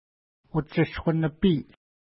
Pronunciation: utʃəsku-nəpi:
Pronunciation